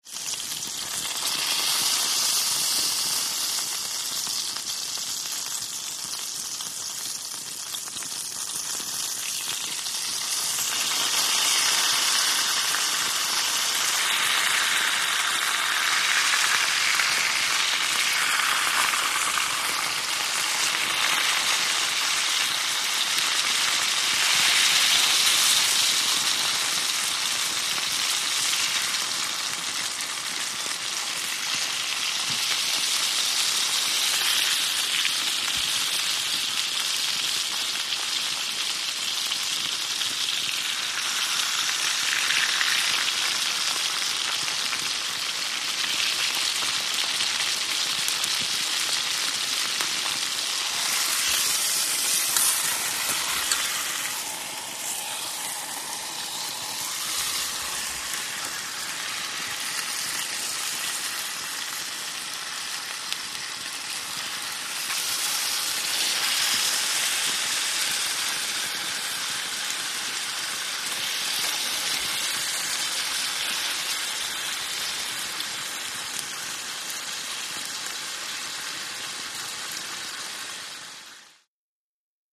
Steak|Exterior | Sneak On The Lot
DINING - KITCHENS & EATING STEAK: EXT: Cooking steak on campfire, close up sizzling.